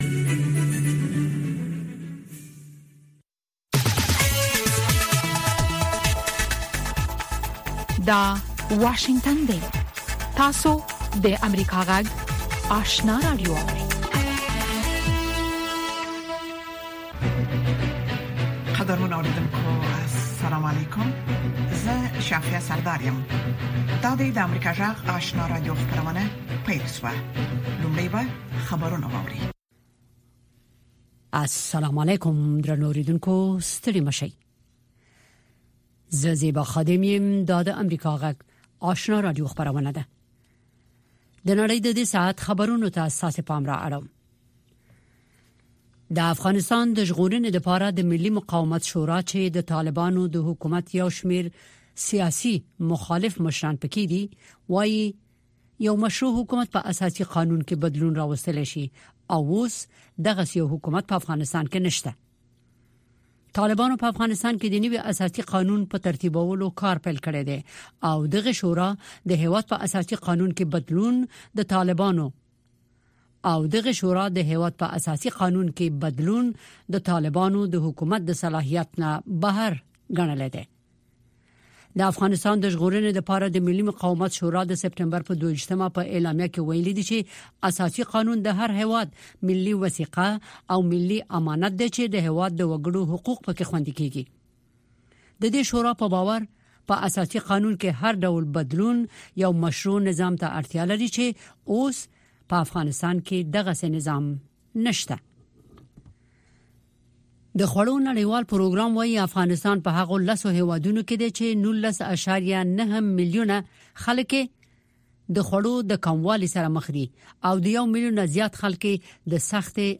په سهارنۍ خپرونه کې د افغانستان او نړۍ تازه خبرونه، څیړنیز رپوټونه او د افغانستان او نړۍ د تودو پیښو په هکله مرکې تاسو ته وړاندې کیږي.